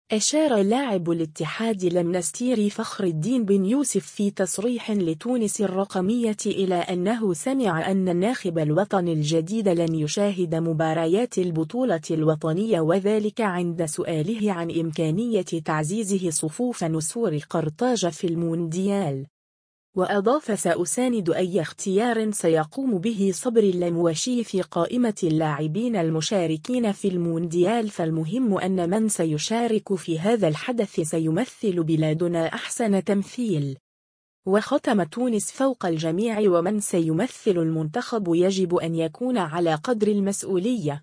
أشار لاعب الإتحاد المنستيري فخر الدين بن يوسف في تصريح لتونس الرقمية إلى أنّه سمع أن الناخب الوطني الجديد لن يشاهد مباريات البطولة الوطنية و ذلك عند سؤاله عن إمكانية تعزيزه صفوف نسور قرطاج في المونديال.